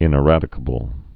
(ĭnĭ-rădĭ-kə-bəl)